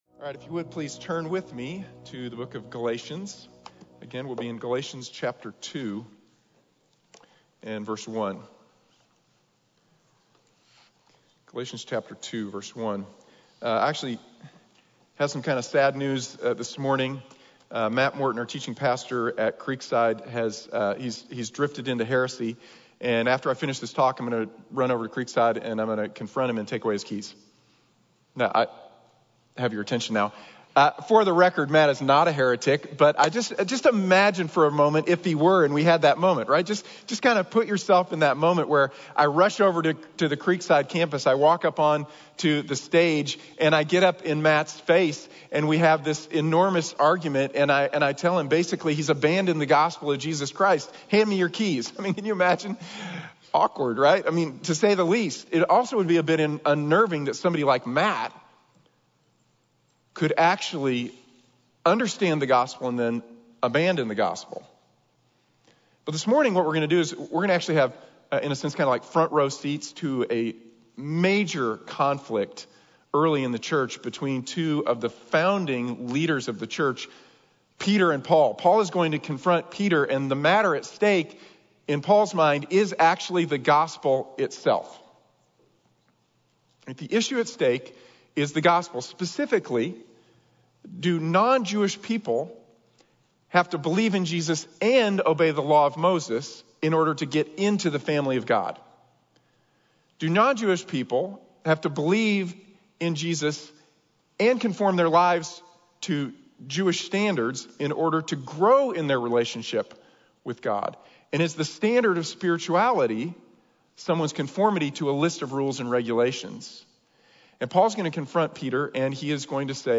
Right with God | Sermon | Grace Bible Church